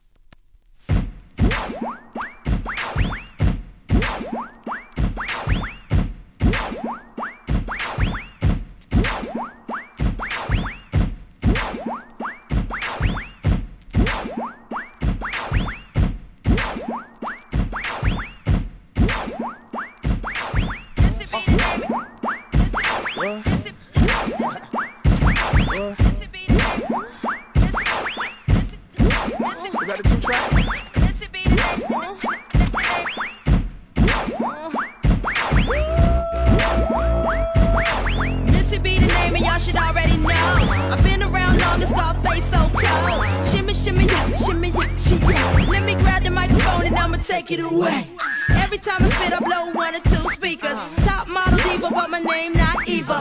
HIP HOP/R&B
現地ヒット曲の数々をDJ USEにアレンジした人気シリーズ!!